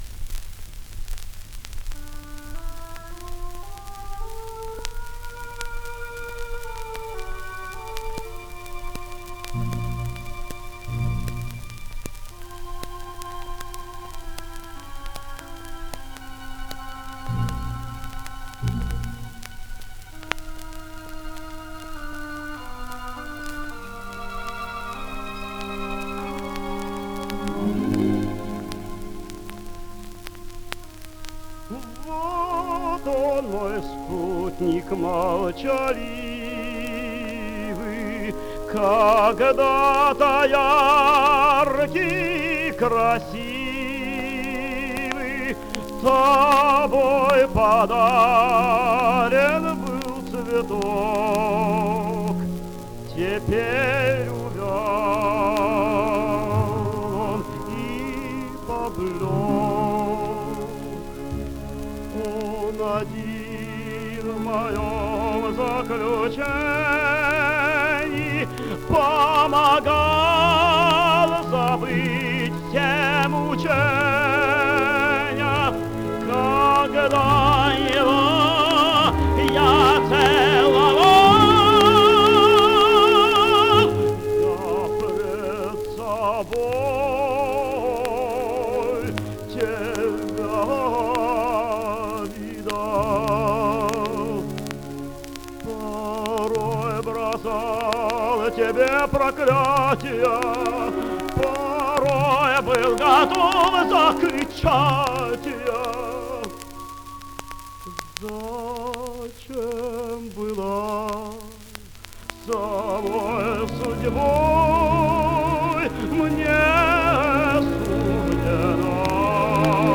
Гефт Бори́с О́сипович [1902, место рождения неизвестно – 1966, Ленинград (ныне Санкт-Петербург); похоронен на Преображенском еврейском кладбище], российский певец (тенор), заслуженный артист РСФСР (1939).
Романс Дона Хозе. Оркестр ГАБТ СССР.
Исполняет Б. О. Гефт.